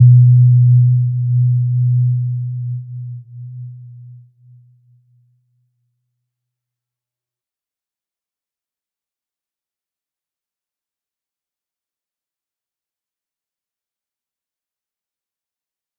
Little-Pluck-B2-p.wav